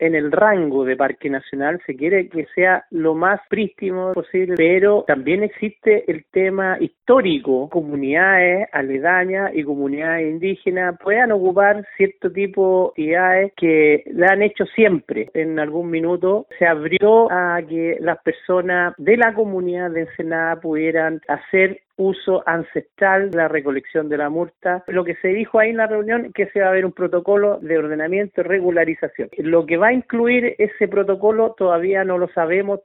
El director regional de Conaf, Miguel Ángel Leiva, afirmó que los ministerios de Agricultura y Medio Ambiente acordaron crear un protocolo, pero no se ha determinado el contenido.